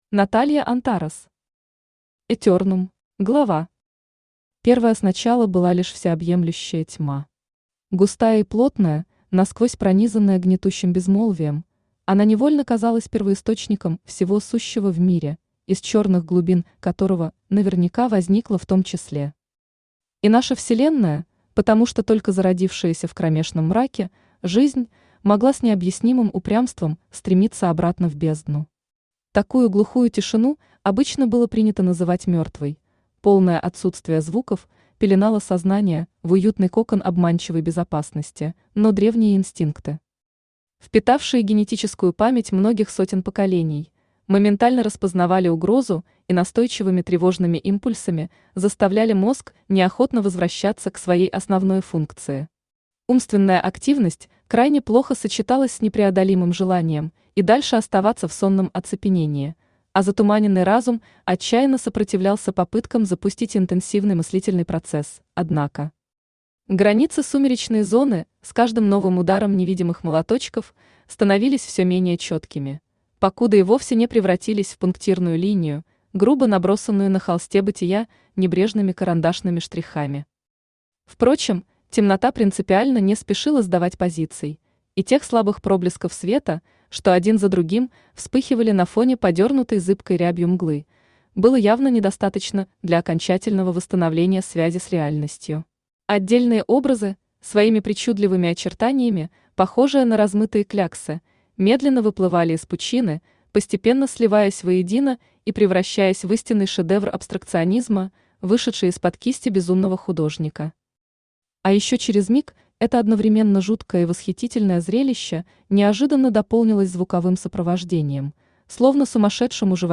Аудиокнига Этернум | Библиотека аудиокниг
Aудиокнига Этернум Автор Наталья Антарес Читает аудиокнигу Авточтец ЛитРес.